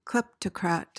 PRONUNCIATION:
(KLEP-tuh-krat)